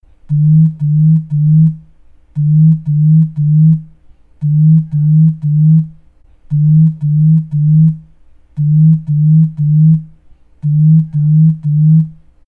دانلود صدای ویبره گوشی از ساعد نیوز با لینک مستقیم و کیفیت بالا
جلوه های صوتی